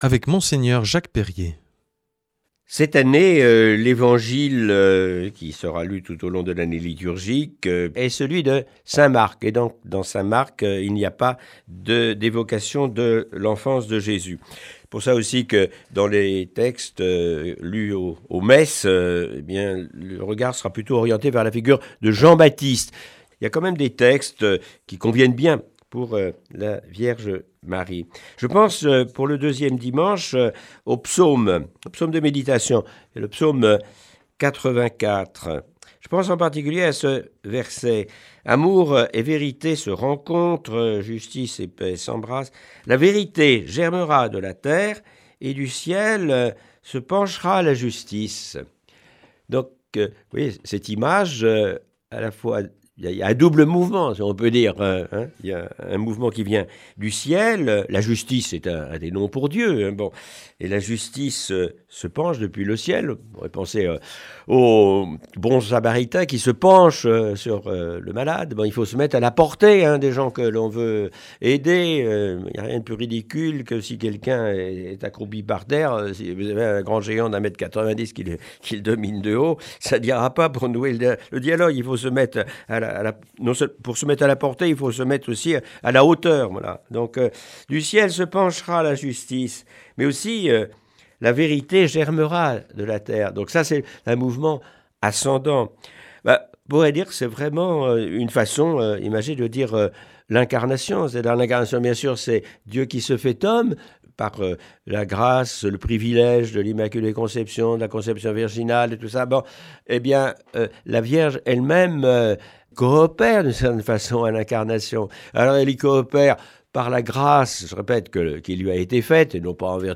Aujourd’hui avec Mgr Jacques Perrier, évèque émérite de Tarbes et Lourdes.